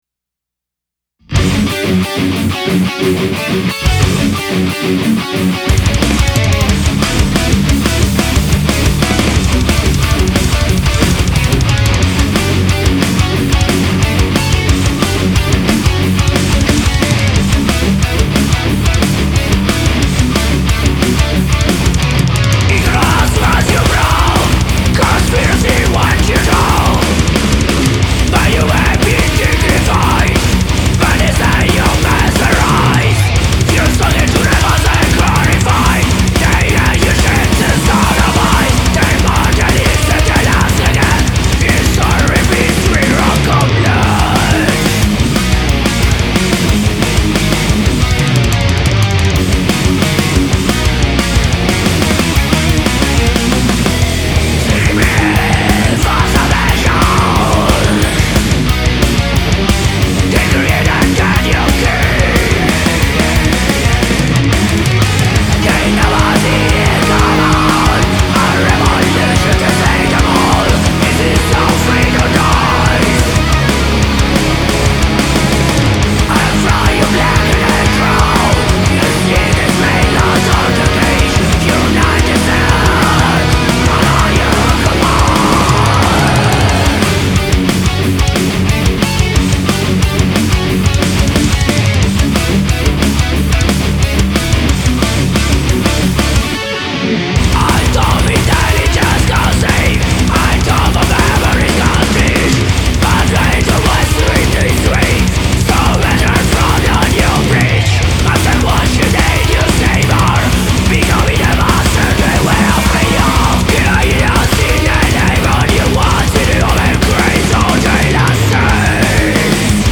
melodic death metal band